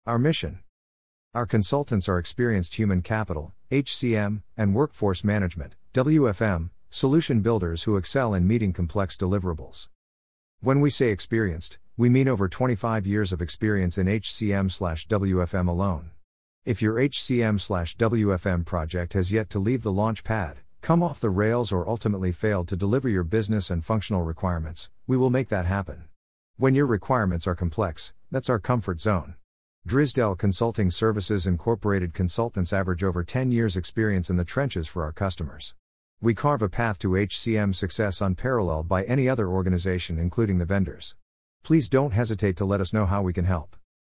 easytts_audio_Our-Mission1-3.mp3